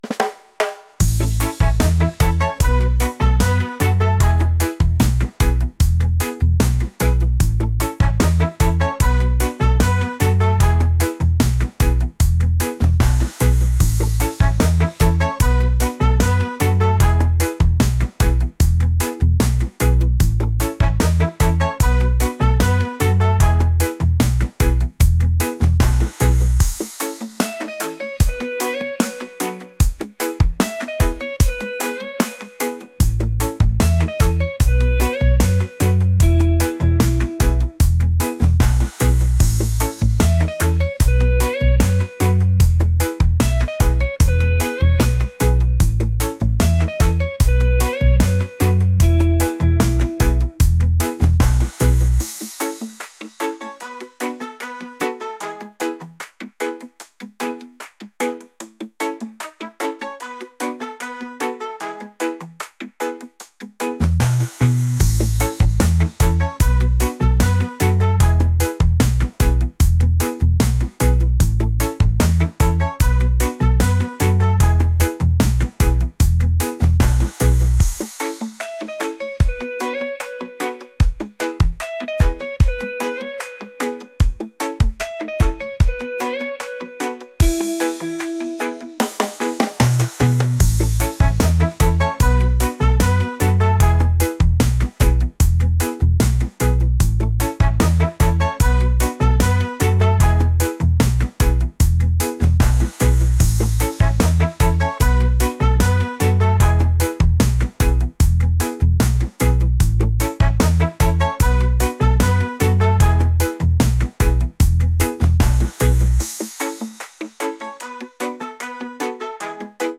reggae | pop | funk